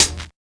100 SCRT HAT.wav